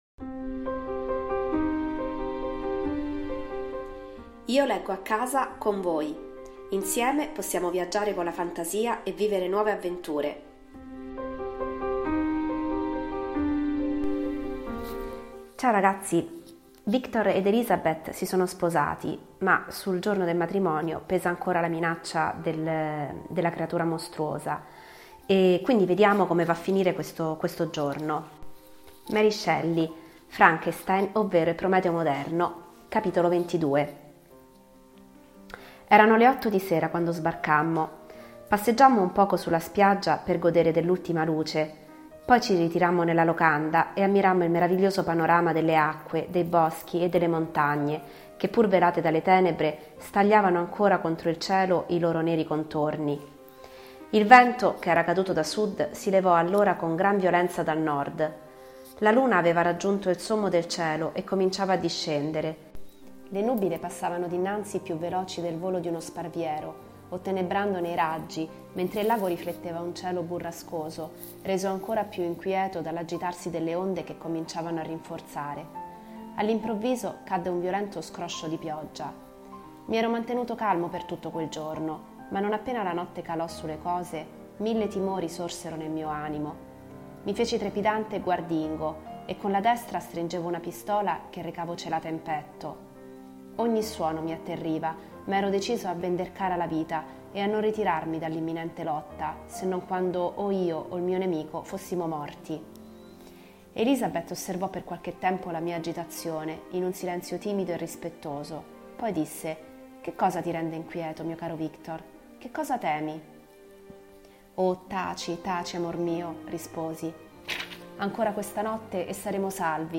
legge, a puntate, “Frankenstein” di Mary Shelley.